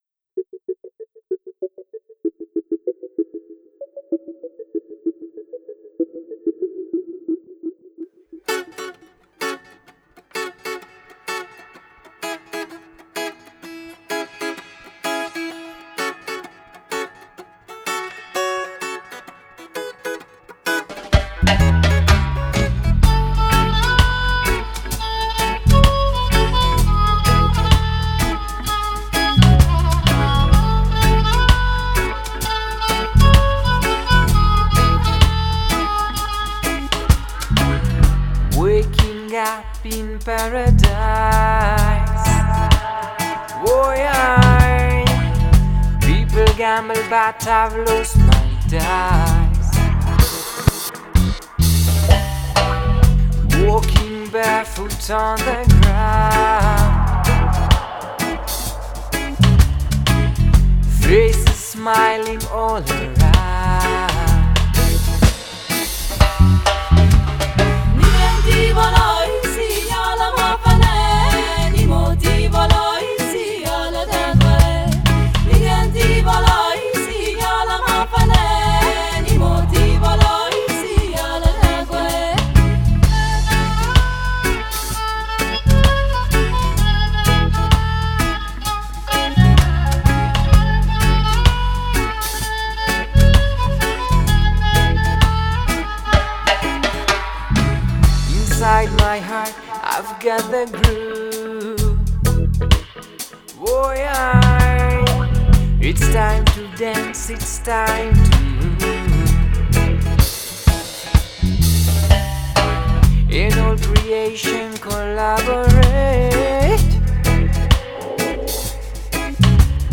Electro – Ethnic – Reggae group
Genre: World